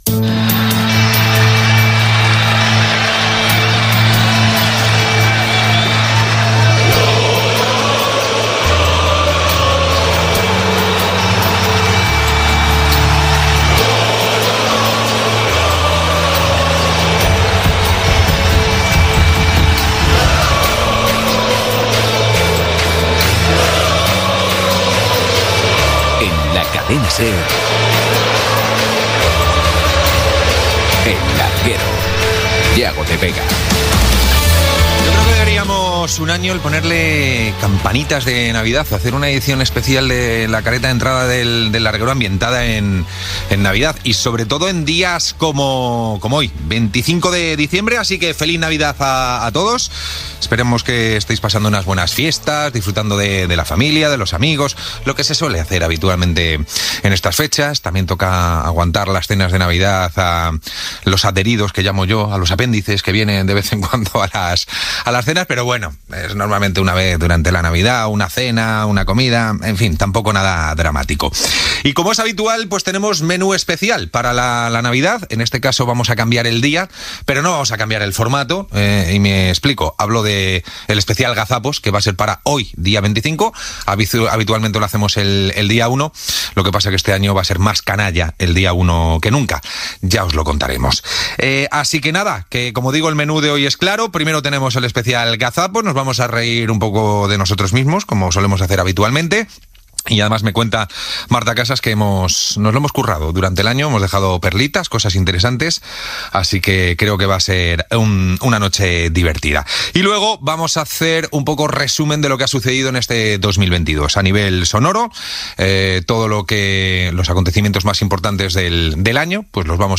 Careta del programa, presentació del programa del dia de Nadal, resum de l'actualitat esportiva, publicitat, presentació del jurat del resum d'errades radiofòniques, audició i comentari dels diversos talls Gènere radiofònic Esportiu